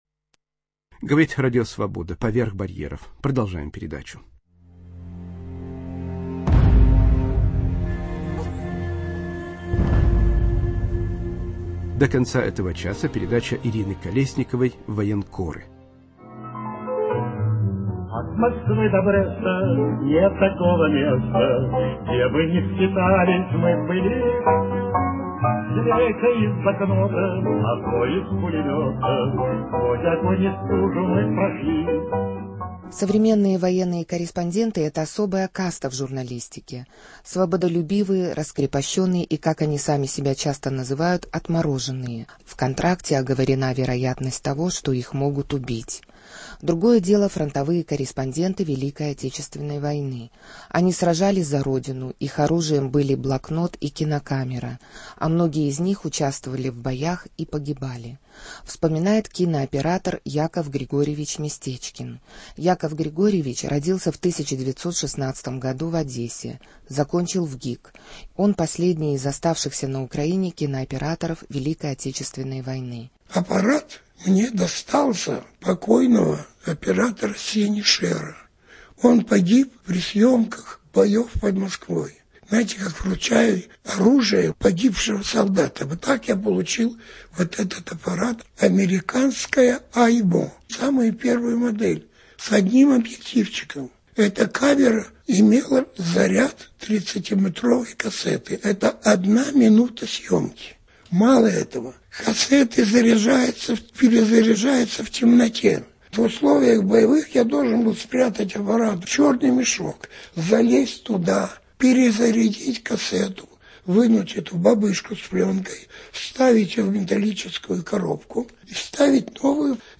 "Военкоры". Разговор с операторами, снимавшими военные действия во время второй мировой войны и войн в Афганистане и в Ираке